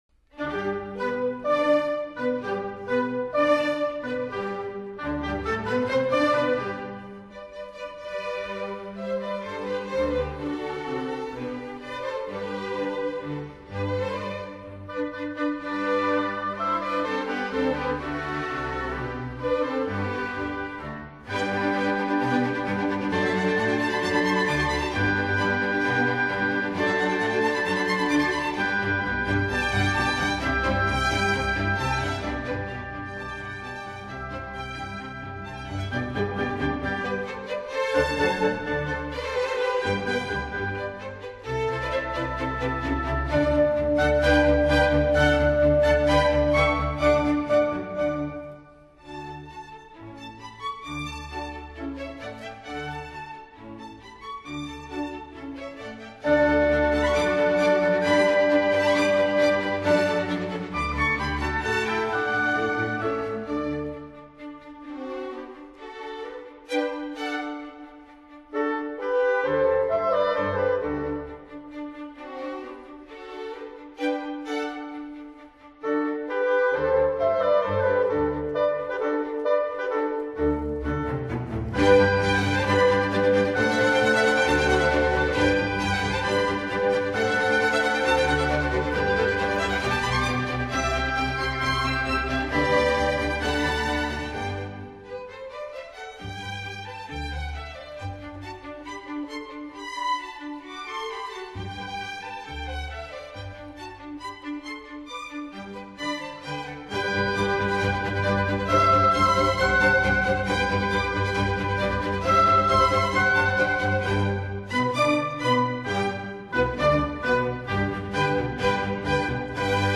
Allegro